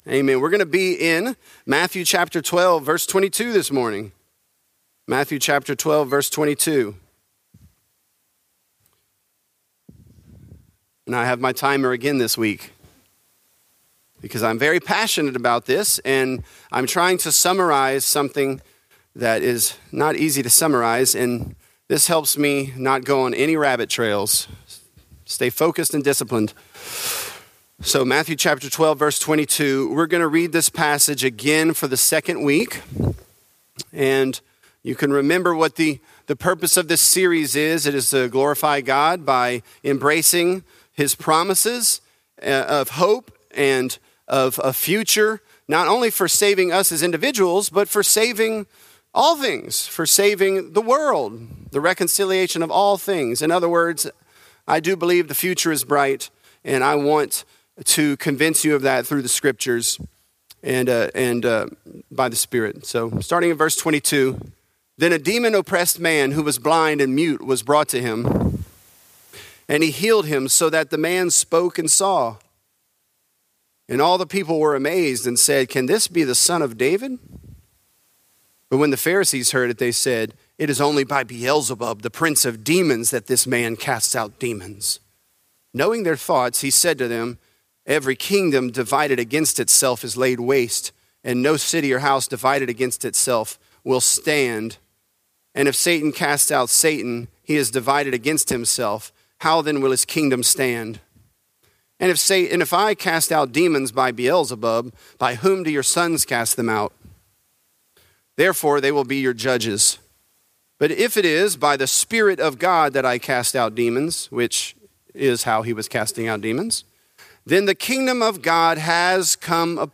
This is a part of our sermon series, "The Kingdom Has Come."